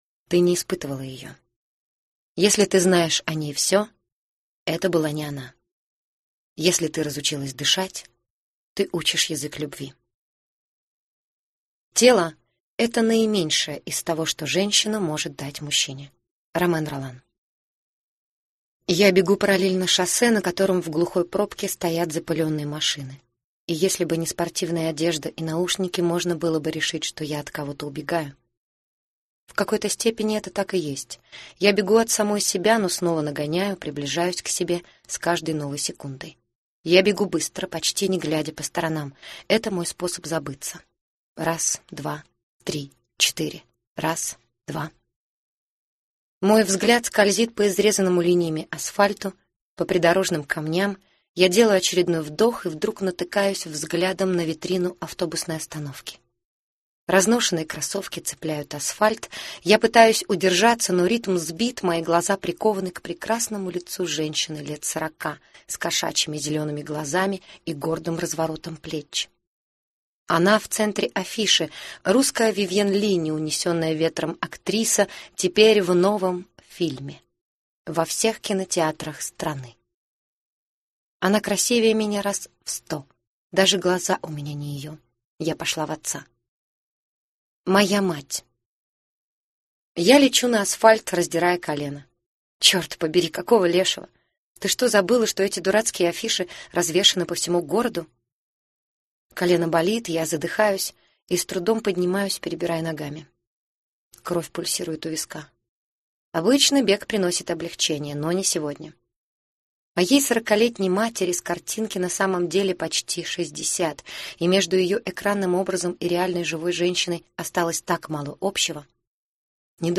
Аудиокнига Полночь по парижскому времени. Амазонка - купить, скачать и слушать онлайн | КнигоПоиск